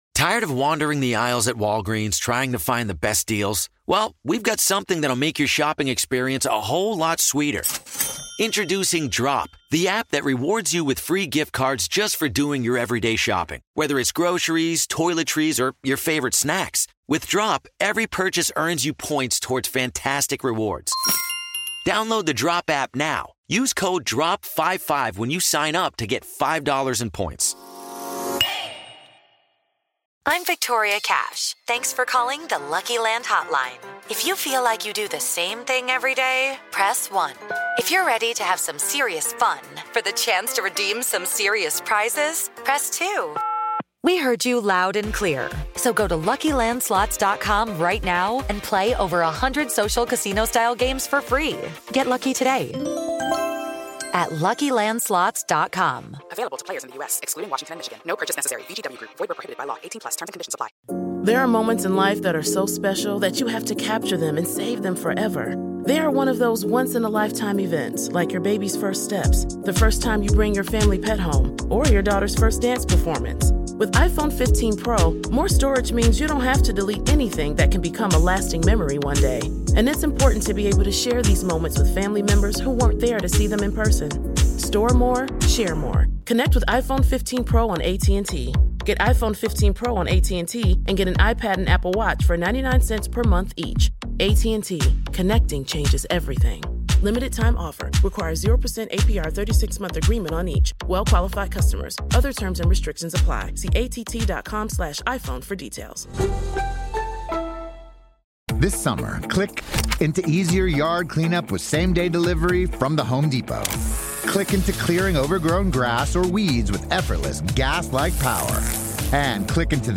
an Independence Day speech for the ages